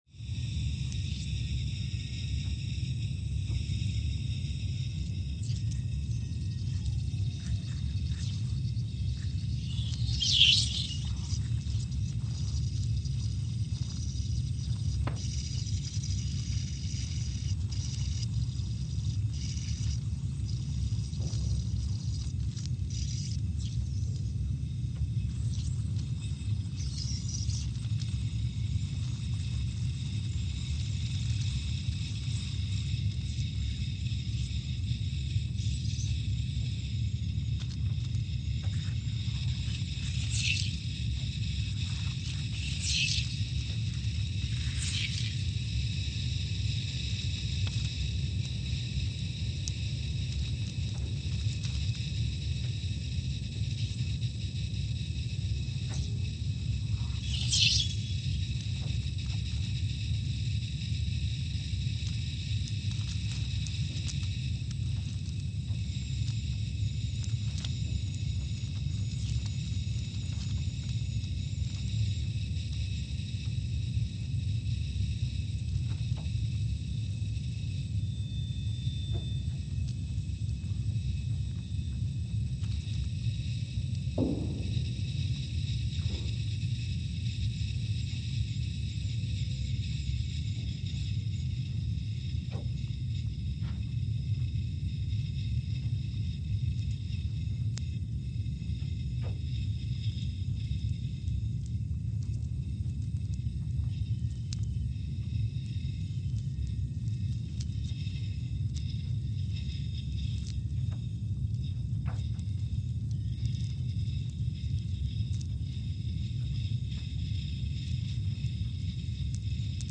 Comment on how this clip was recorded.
Scott Base, Antarctica (seismic) archived on July 23, 2022 Sensor : CMG3-T Speedup : ×500 (transposed up about 9 octaves) Loop duration (audio) : 05:45 (stereo) SoX post-processing : highpass -2 90 highpass -2 90